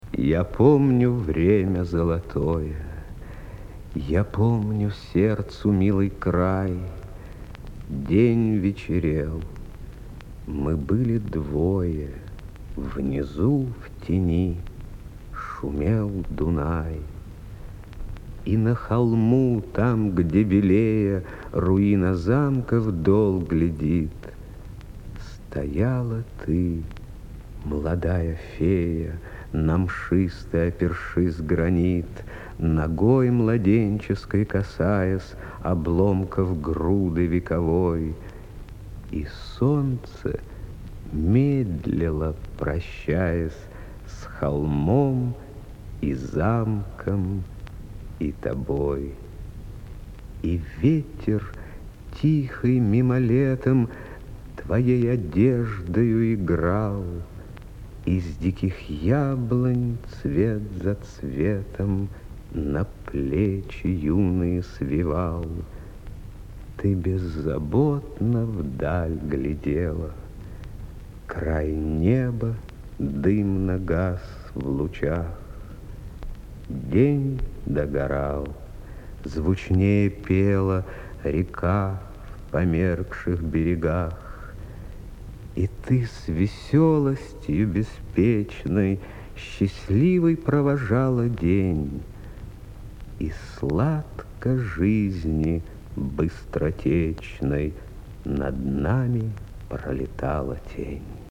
Музыкальное сопровождение.